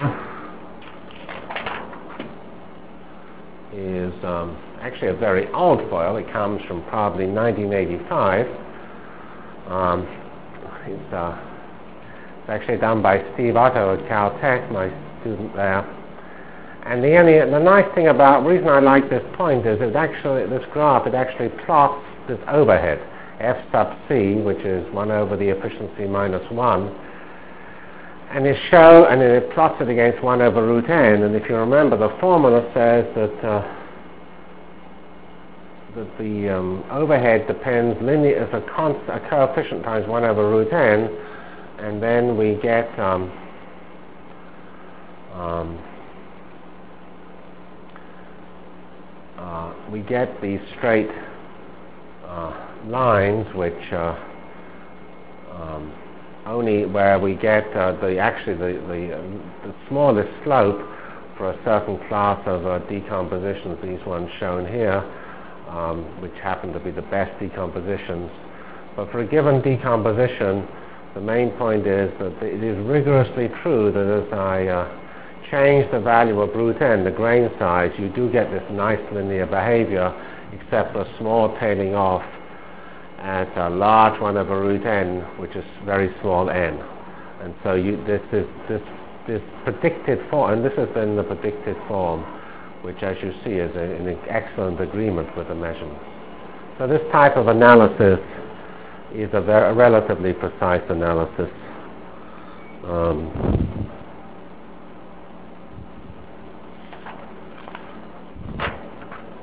From CPS615-Lecture on Performance(end) and Computer Technologies(start) Delivered Lectures of CPS615 Basic Simulation Track for Computational Science -- 10 September 96.